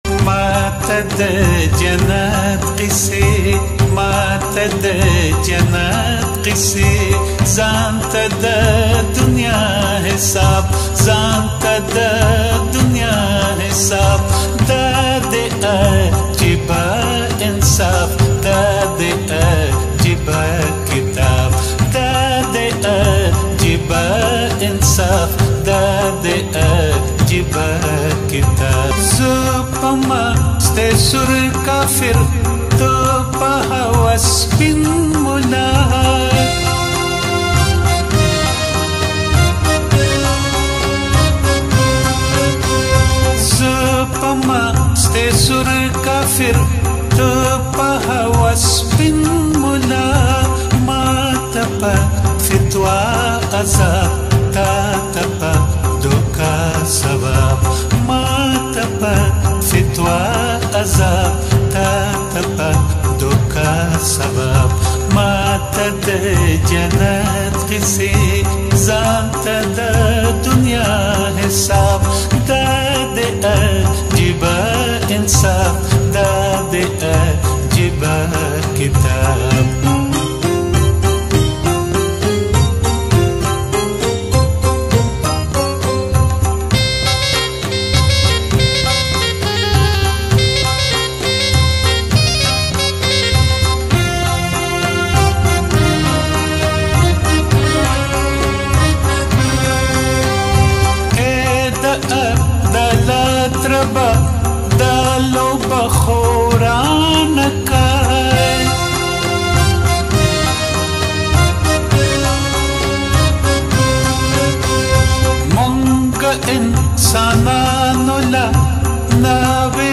ټنګ ټکور